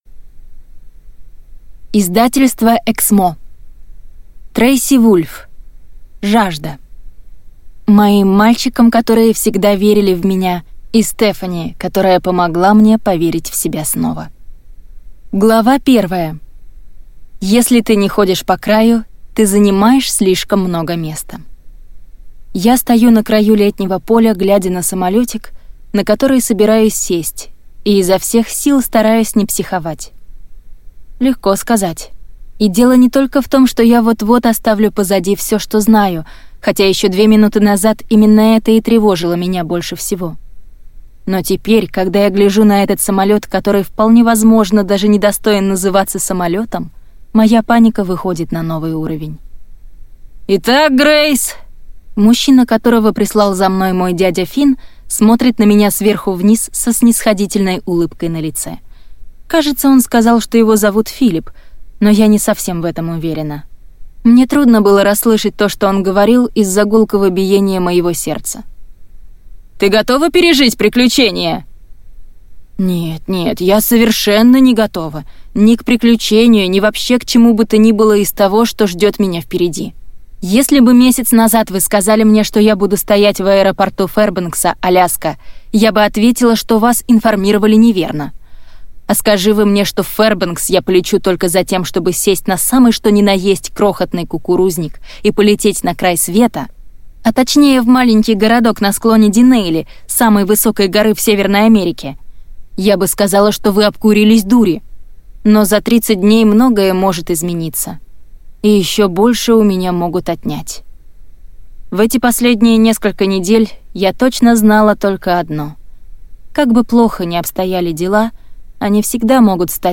Аудиокнига Жажда | Библиотека аудиокниг
Прослушать и бесплатно скачать фрагмент аудиокниги